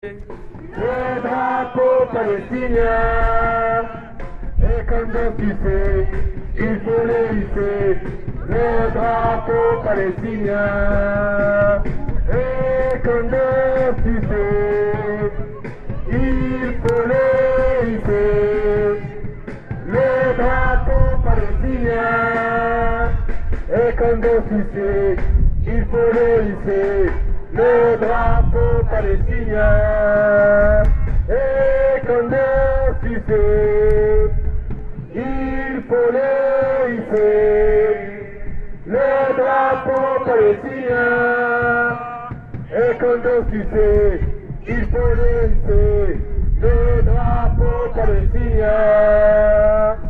Enregistrements des prises de paroles et des slogans de la manifestation.
Chanson, place de la libération,